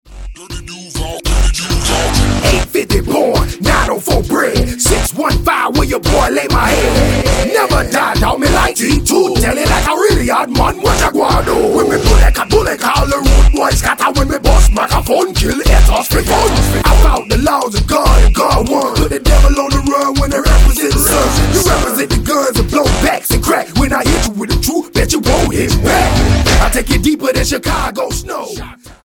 the militant crunk sound
Hip-Hop